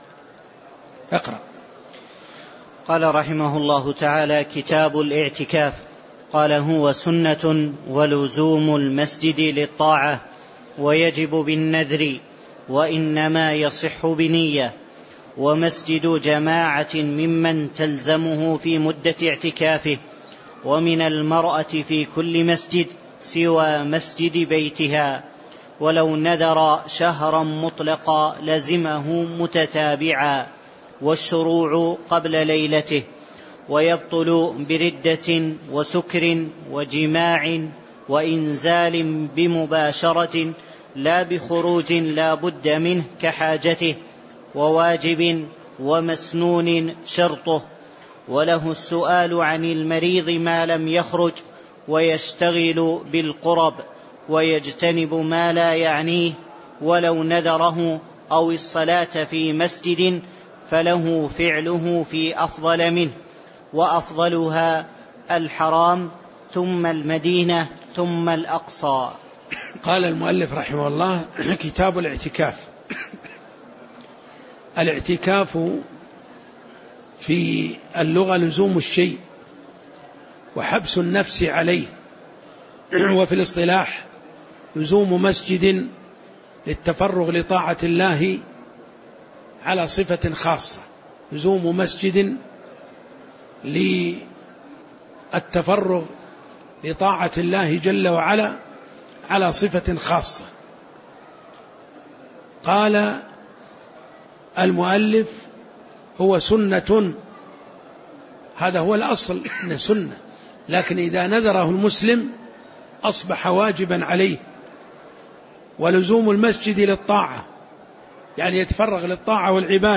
تاريخ النشر ١٨ شوال ١٤٣٩ هـ المكان: المسجد النبوي الشيخ